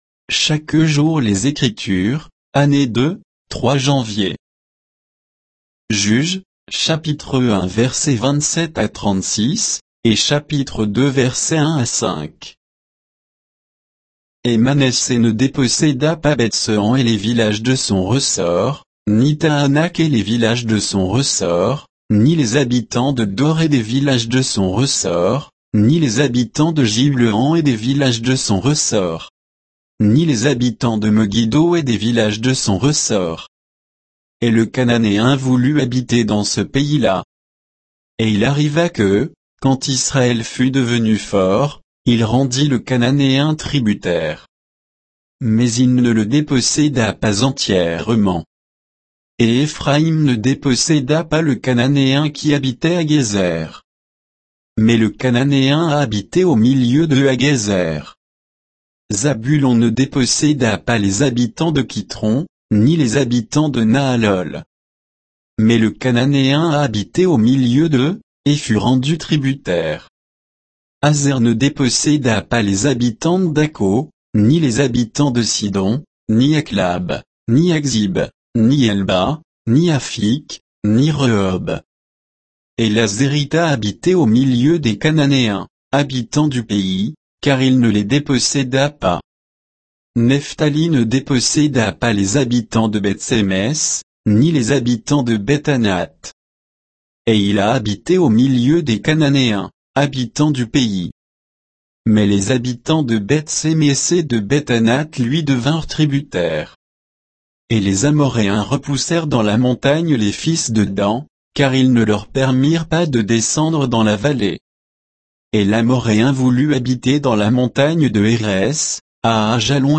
Méditation quoditienne de Chaque jour les Écritures sur Juges 1